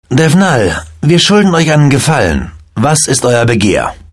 The characters' voices are well chosen and mostly pleasant.